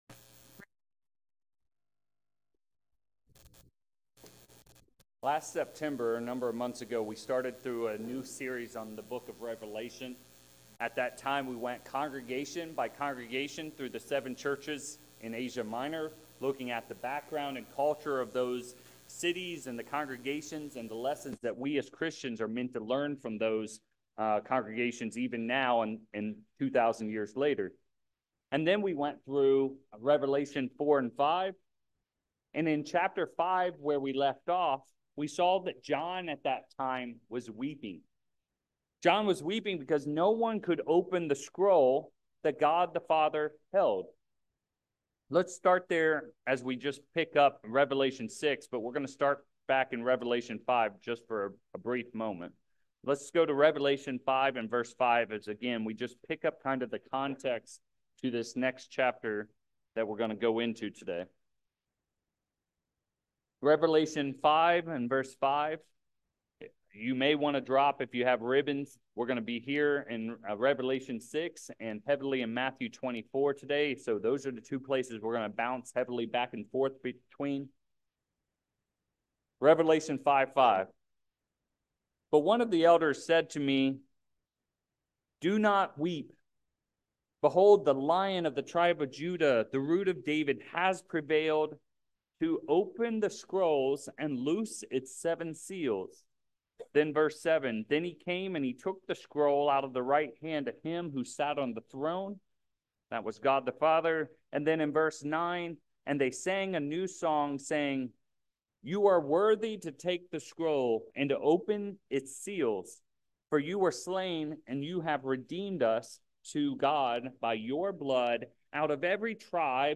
3/23/24 In this sermon, we continue our series through the book of Revelation.